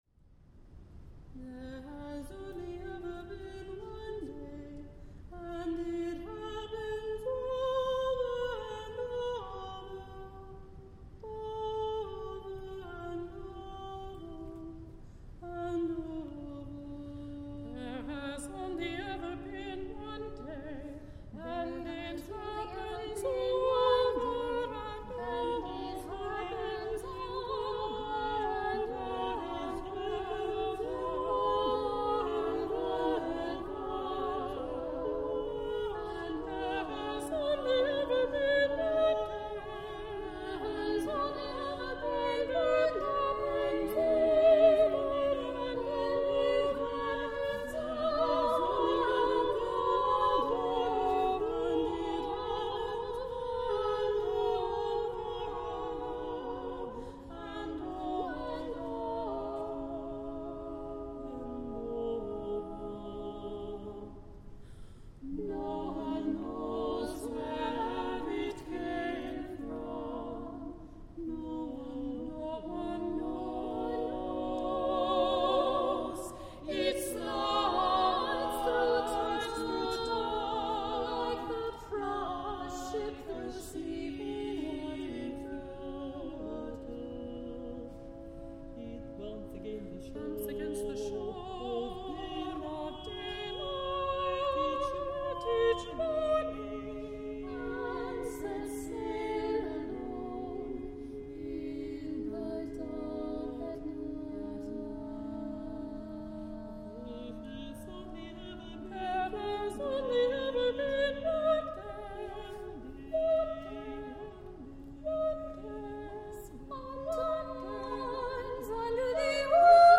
SSAA a cappella
This is a setting for women’s voices